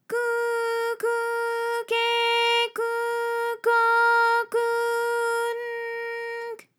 ALYS-DB-001-JPN - First Japanese UTAU vocal library of ALYS.
ku_ku_ke_ku_ko_ku_n_k.wav